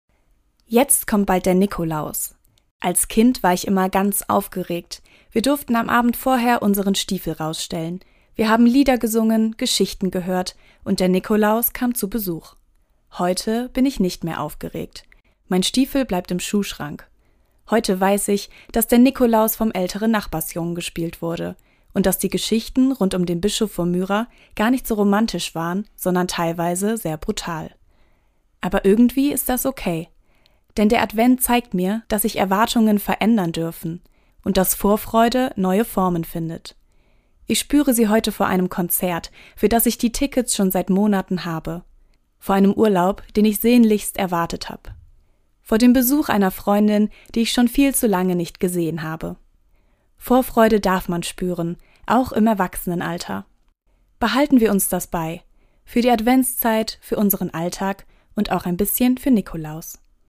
Autorin und Sprecherin ist